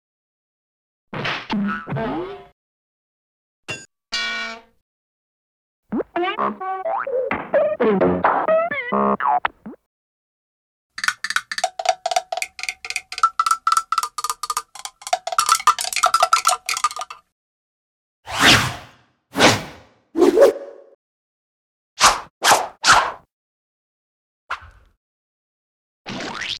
Cartoon Sound Effect Download: Instant Soundboard Button
Play and download the Cartoon sound effect buttons instantly!